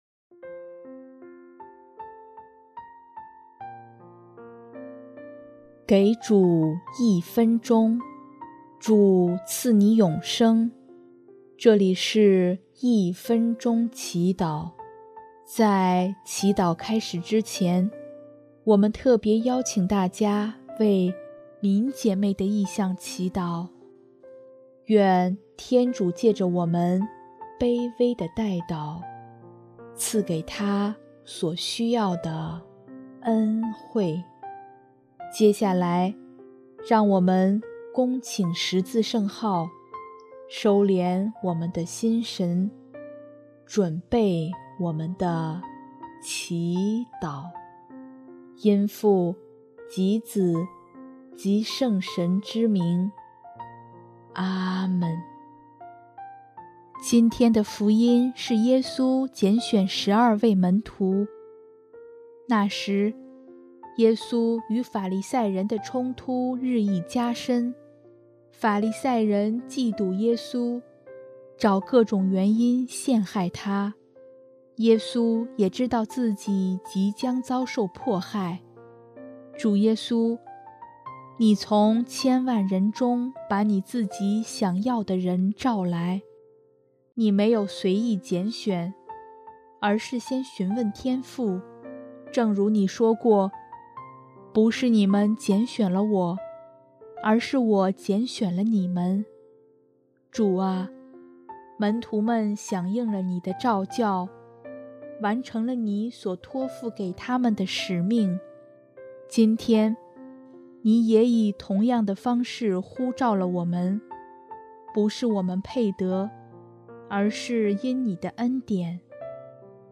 首页 / 祈祷/ 一分钟祈祷